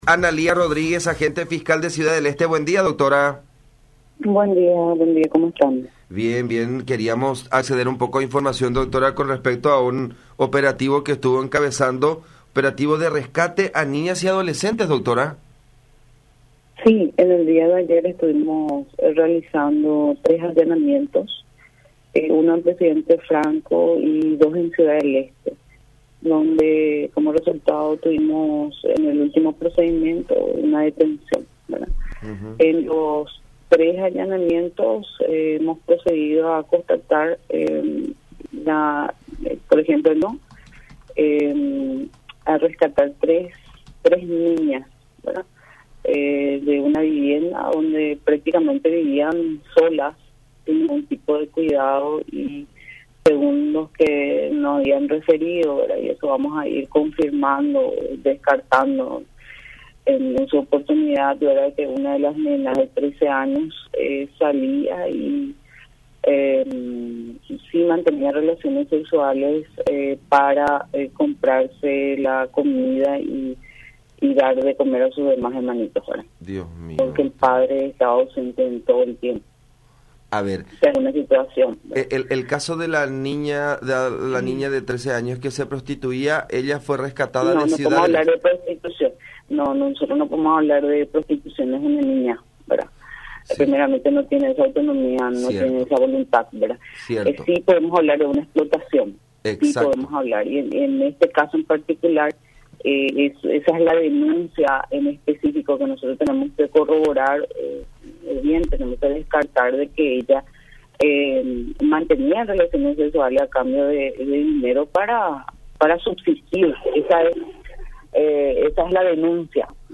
“En uno de los allanamientos encontramos a tres niñas que vivían prácticamente solas”, detalló la fiscal interviniente, Analía Rodríguez, en conversación con La Unión, afirmando que el momento más desgarrador del operativo realizado en Presidente Franco fue cuando una de las menores de 13 años declaró que se prostituía para poder alimentar a sus hermanitos.
04-Dra.-Analía-Rodríguez-Agente-Fiscal-sobre-rescate-de-menores-explotados-sexualmente-en-CDE.mp3